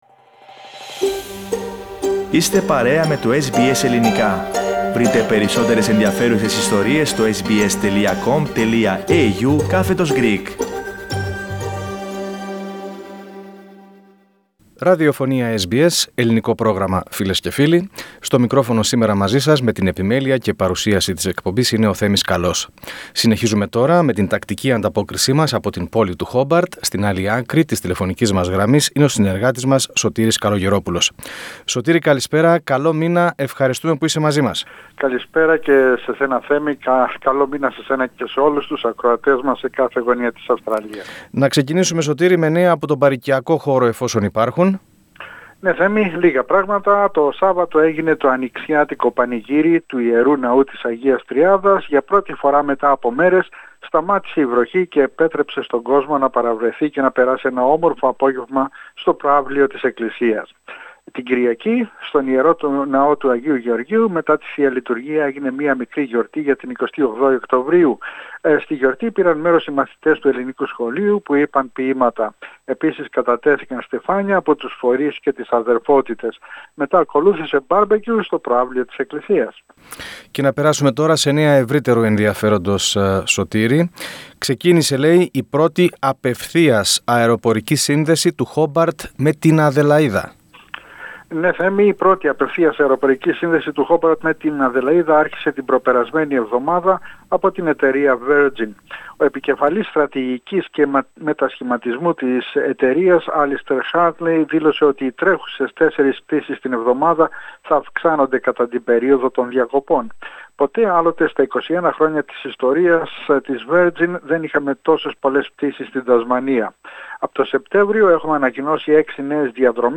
ΔΕΙΤΕ ΑΚΟΜΗ Επικρίσεις δέχονται οι παγκόσμιοι ηγέτες για την κλιματική αλλαγή Πατήστε PLAY για να ακούσετε την ανταπόκριση του SBS Greek/SBS Ελληνικά από την Τασμανία.